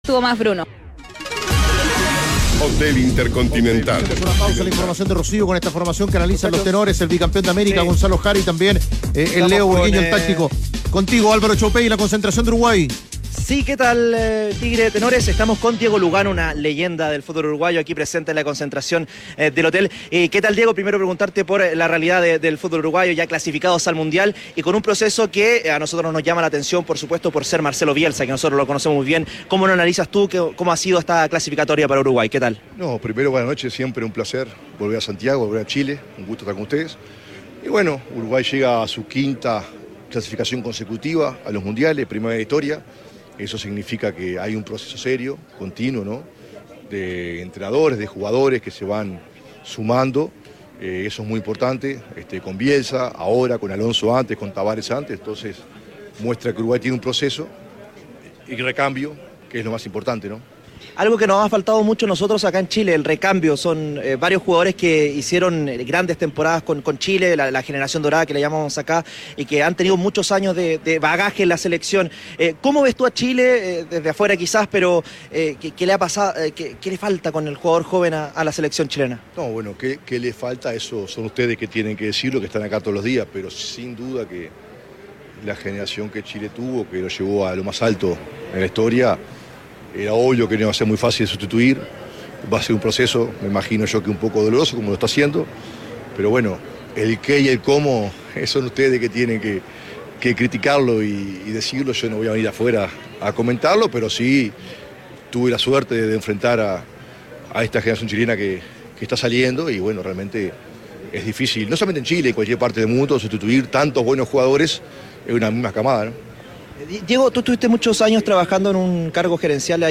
Diego Lugano, histórico defensor de la selección uruguaya, conversó con ADN Deportes en la previa del partido ante La Roja por Eliminatorias.